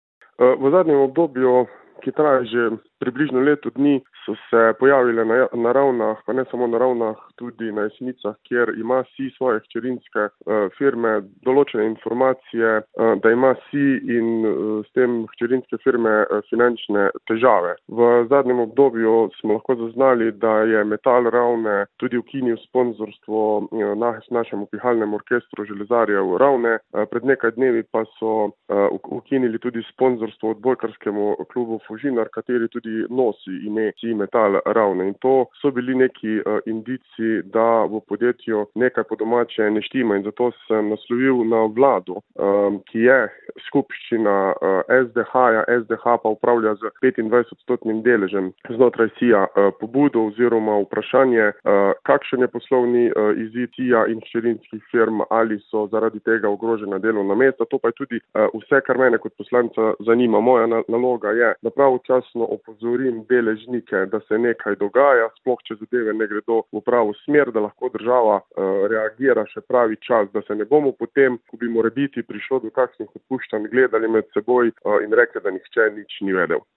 izjava Jani 1 ZA SPLET.mp3